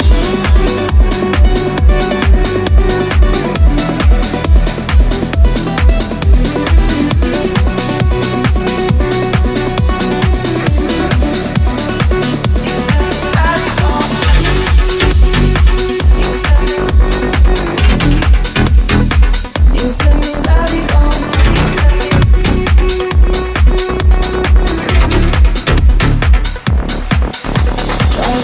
Genere: deep,dance,news